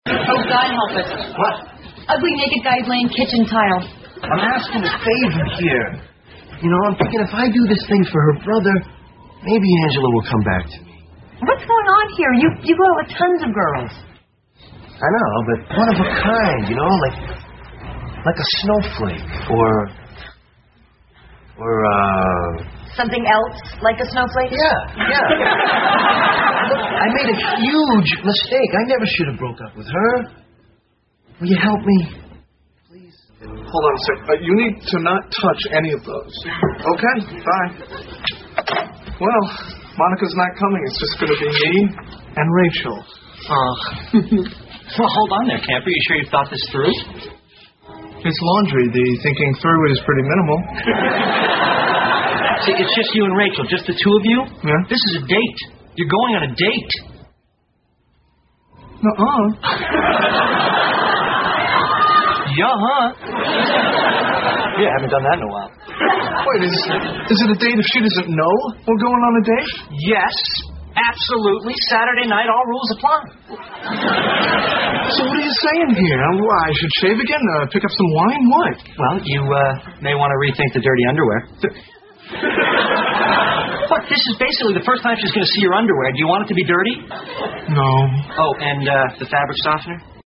在线英语听力室老友记精校版第1季 第51期:洗衣服(4)的听力文件下载, 《老友记精校版》是美国乃至全世界最受欢迎的情景喜剧，一共拍摄了10季，以其幽默的对白和与现实生活的贴近吸引了无数的观众，精校版栏目搭配高音质音频与同步双语字幕，是练习提升英语听力水平，积累英语知识的好帮手。